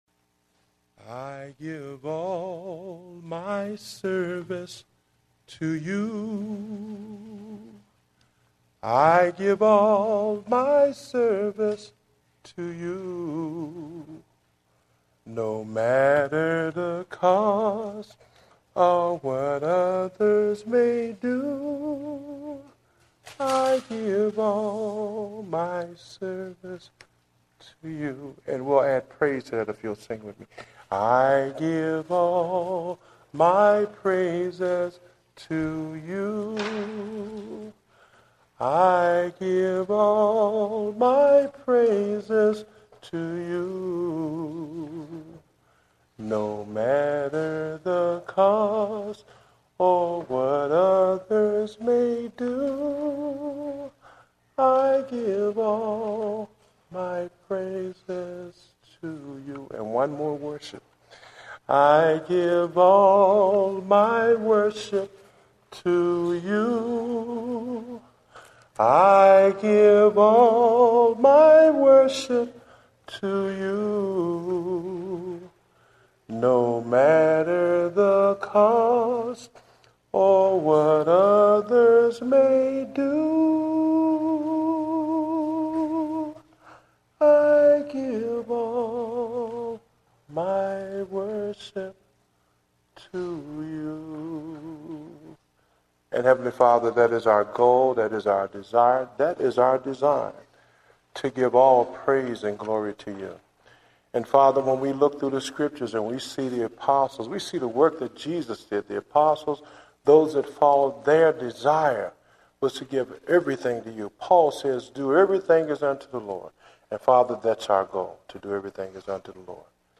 Date: July 11, 2010 (Morning Service)